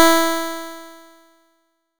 nes_harp_E4.wav